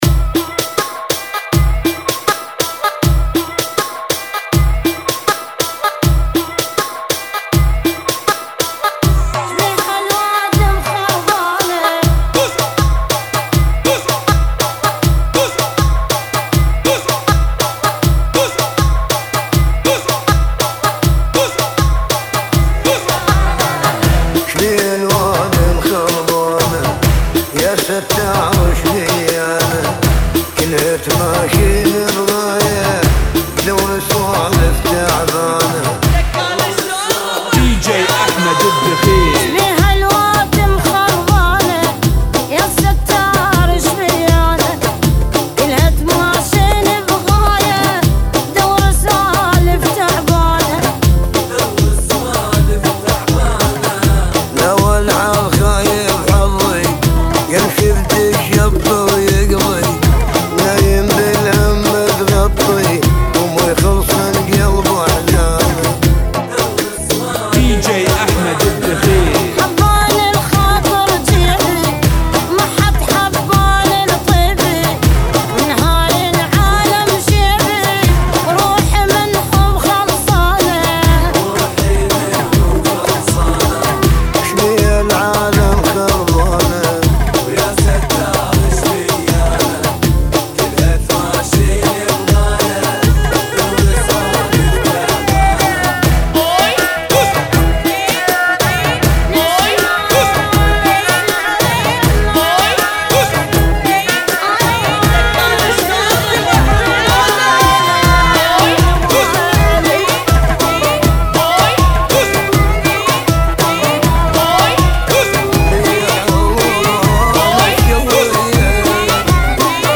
ريمكس